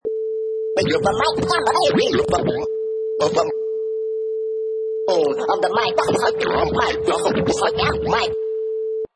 Professional killer vinyl scratch perfect for sampling, mixing, music production, timed to 105 beats per minute
Category: Musical Instruments / Turntables
Try preview above (pink tone added for copyright).
Tags: scratches